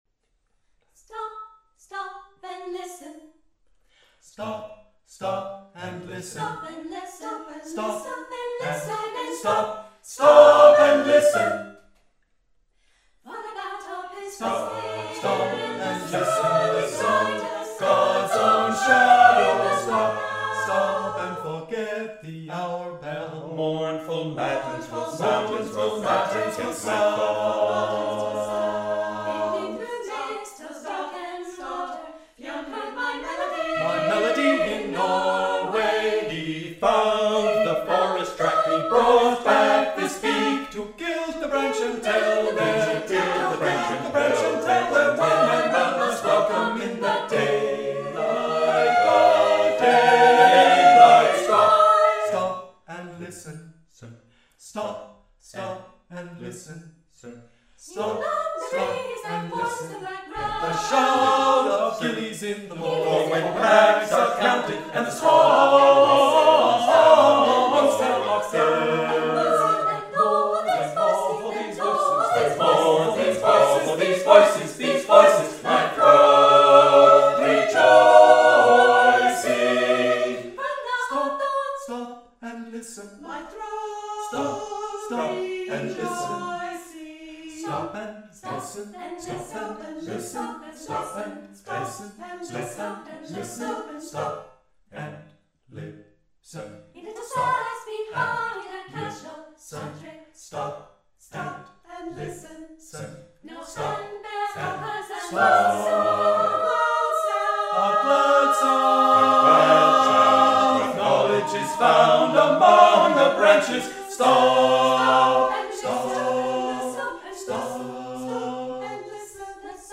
SATB